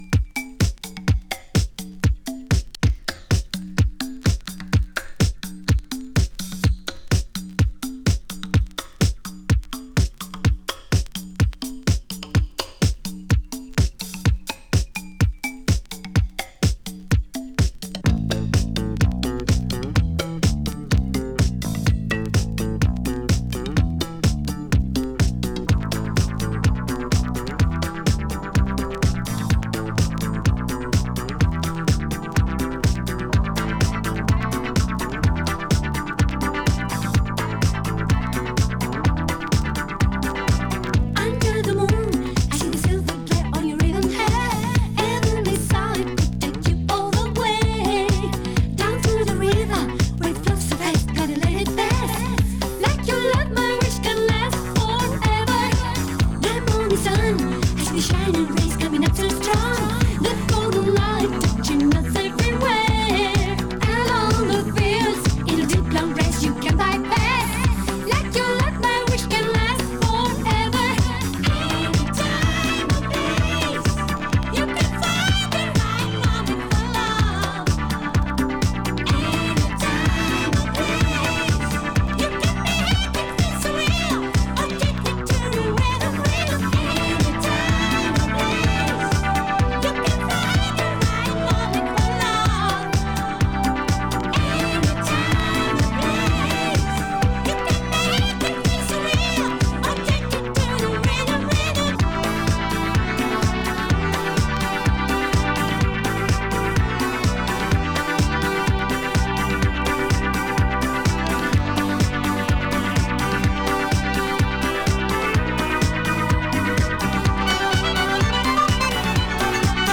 Italo Disco Classic！
DJ Harveyもプレイしていたエモーショナルな女性ヴォーカルをフィーチャーしたディスコ・ナンバー！